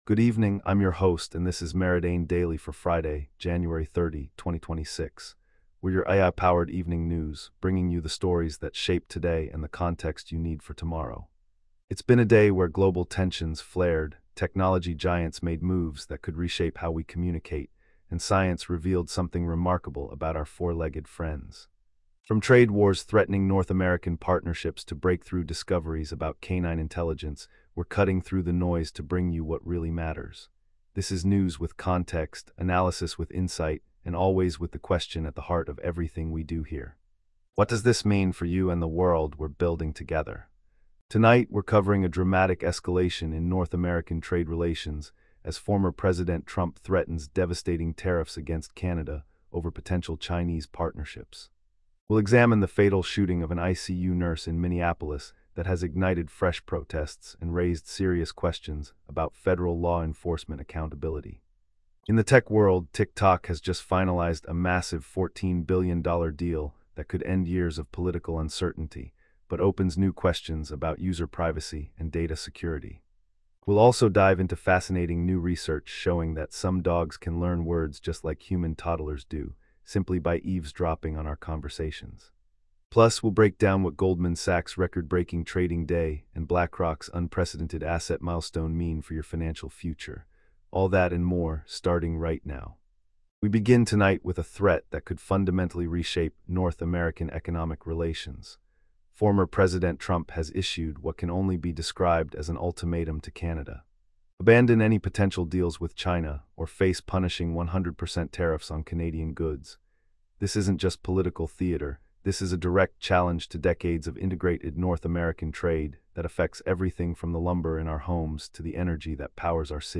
Your nightly AI-powered news briefing for Jan 30, 2026